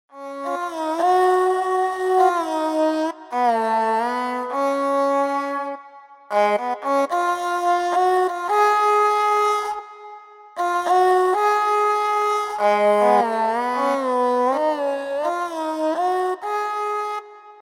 Ravana Haththa
Ravana Haththa is the oldest violine in the world.
ravanhattha.mp3